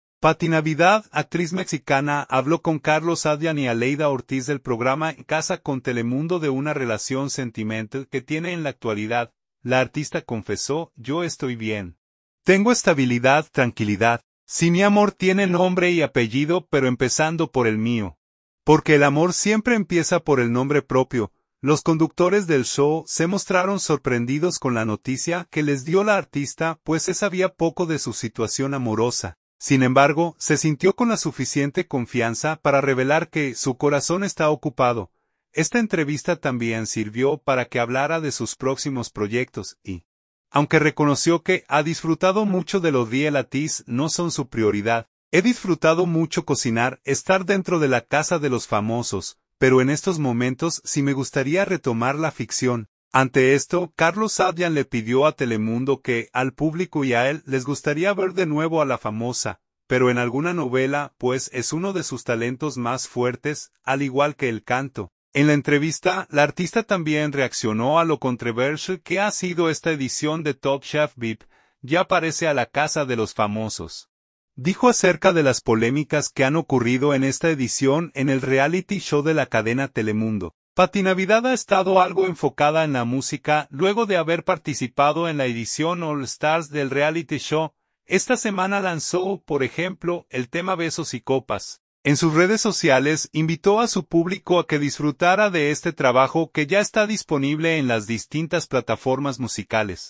Paty Navidad, actriz mexicana, habló con Carlos Adyan y Aleyda Ortiz del programa En Casa con Telemundo de una relación sentimental que tiene en la actualidad.